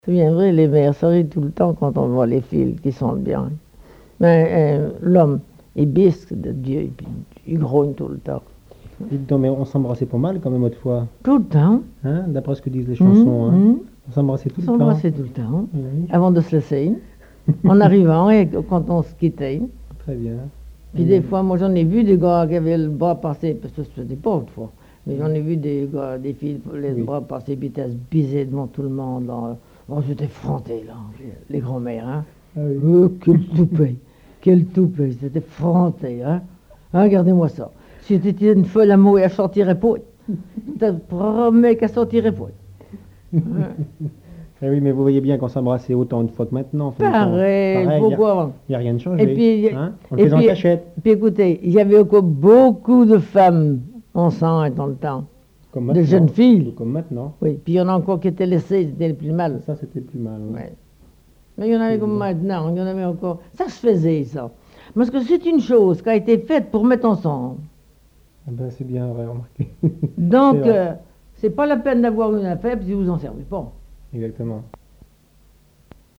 collecte en Vendée
Catégorie Témoignage